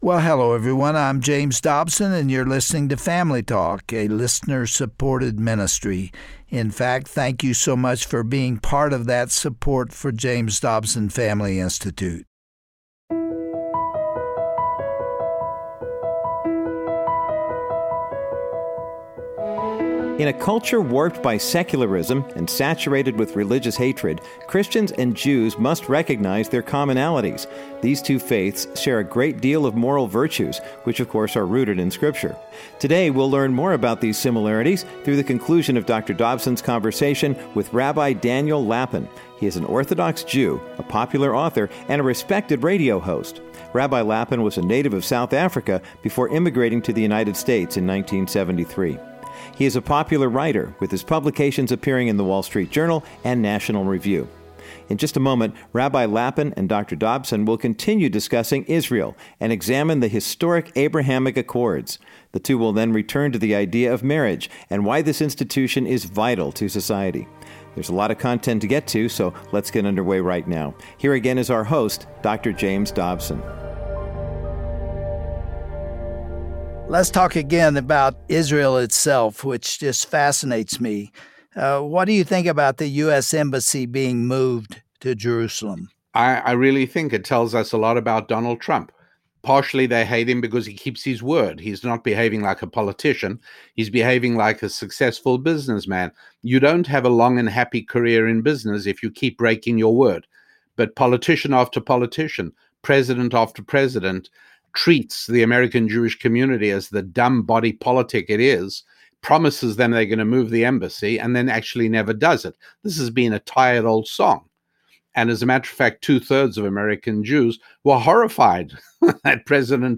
Dr. James Dobson and Rabbi Daniel Lapin discuss the state of Israel on the world stage. They also describe God's design for marriage and the foundational role it has played in every civilized society.